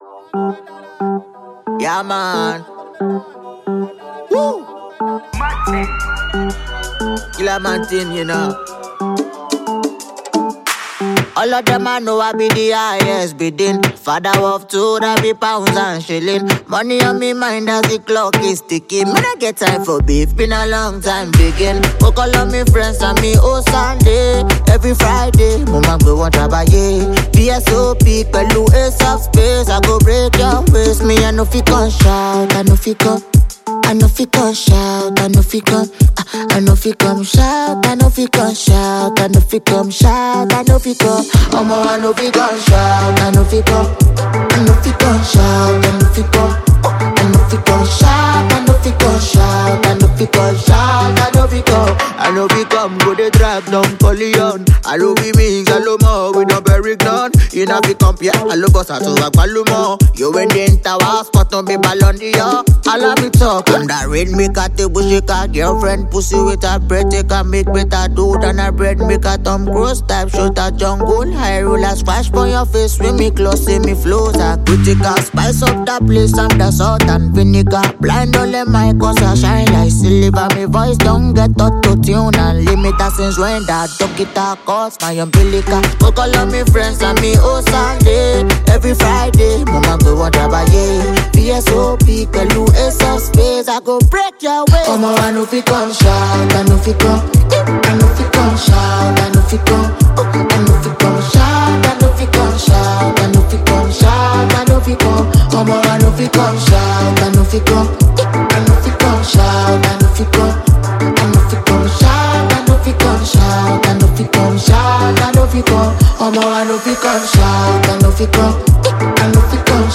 Famous Nigerian hip-hop singer